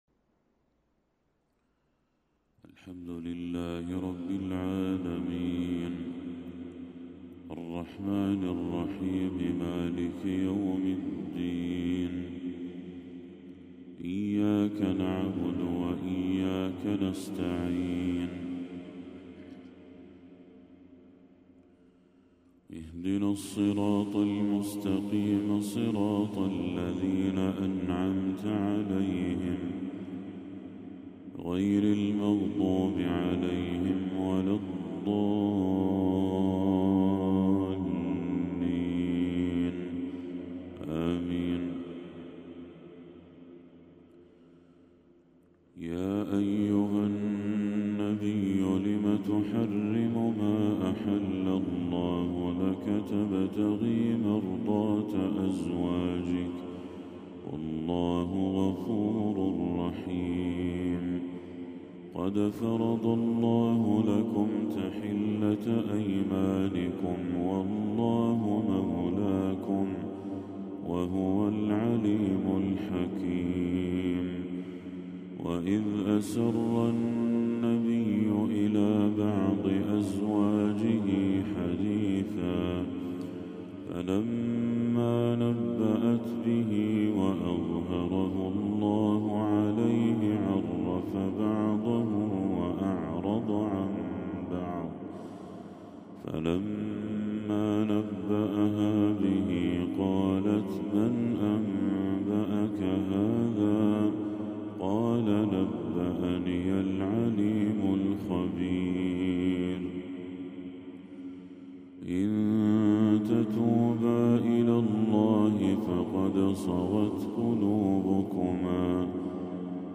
تلاوة هادئة لسورة التحريم كاملة للشيخ بدر التركي | فجر 27 ربيع الأول 1446هـ > 1446هـ > تلاوات الشيخ بدر التركي > المزيد - تلاوات الحرمين